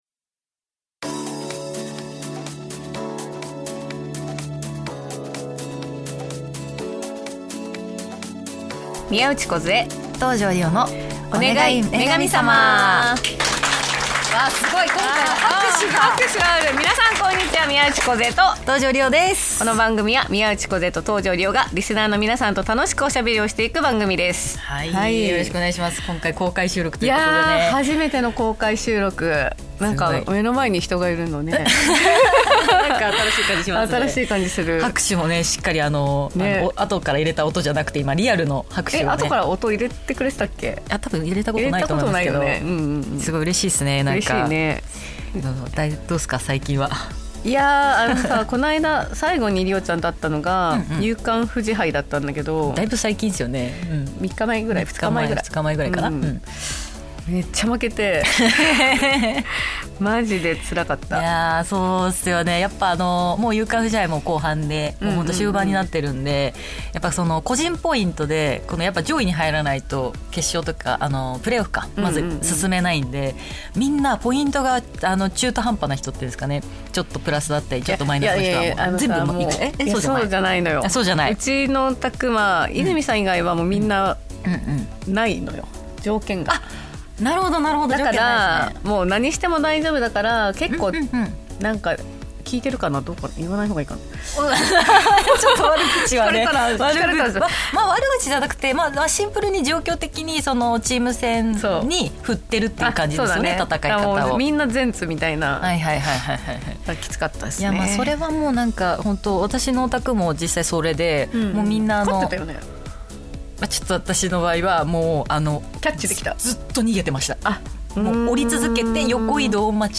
番組初の公開収録が開催されました！